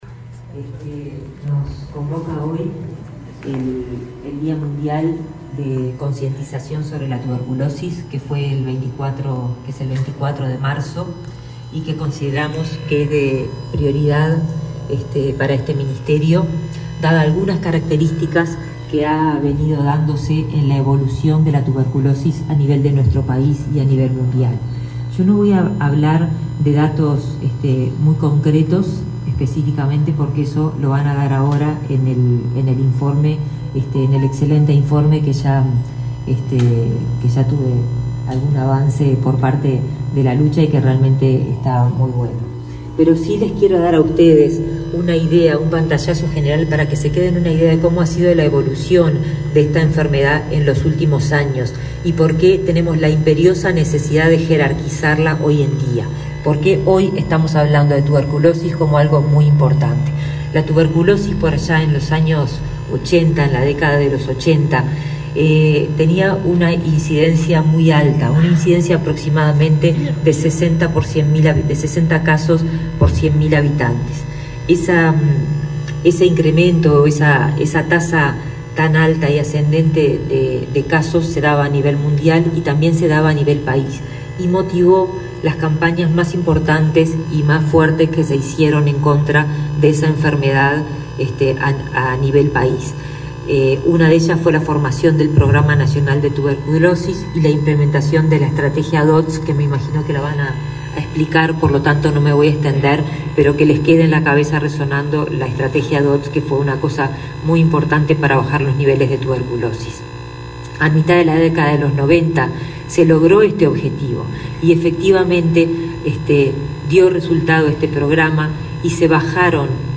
Palabras de la ministra de Salud Pública, Karina Rando
En el marco del Día Mundial de la Tuberculosis, el Ministerio de Salud Pública (MSP) participó en el acto realizado este 28 de marzo por la Comisión
En el evento disertó la ministra Karina Rando.